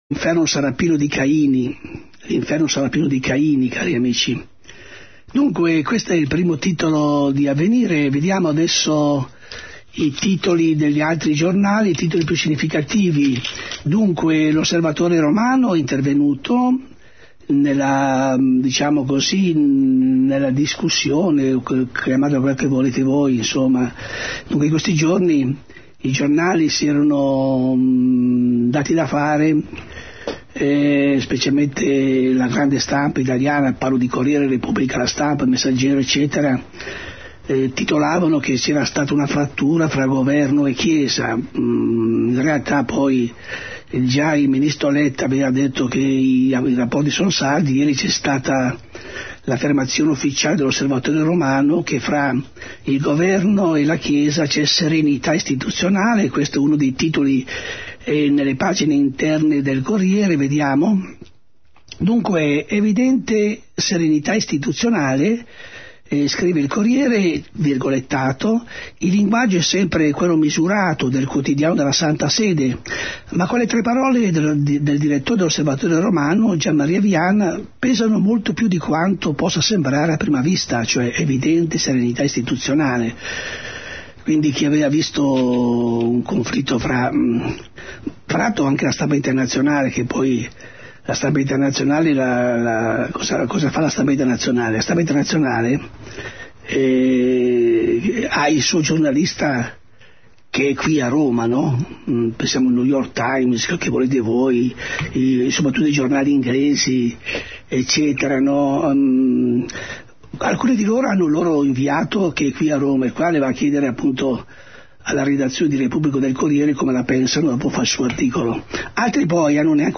Radio Maria è un’emittente esclusivamente religiosa.
estratto dalla "Commento alla stampa del giorno" di Martedì 8 Settembre 2009